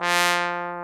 Index of /90_sSampleCDs/Roland L-CDX-03 Disk 2/BRS_Trombone/BRS_Tenor Bone 3